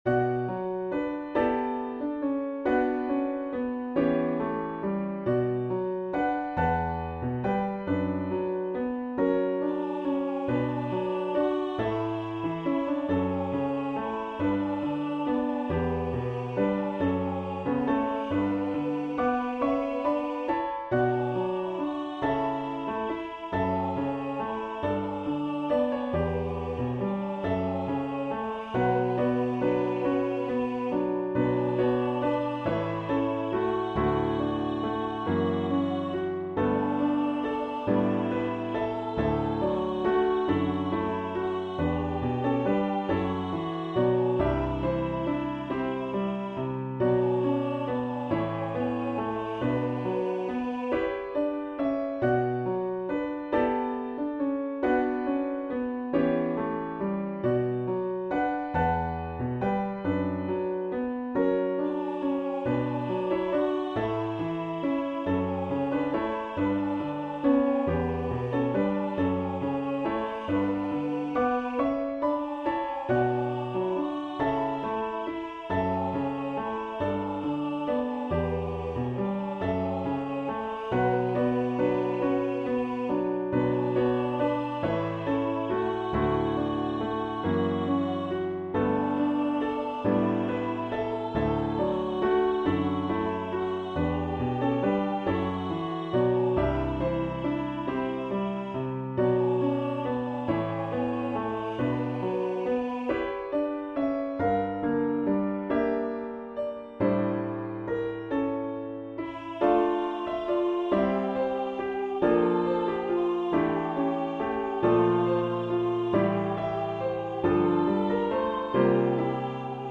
Piano-Vocal Arrangement
Solo or Unison - children, youth, or adults
Vocal Solo Medium Voice/Low Voice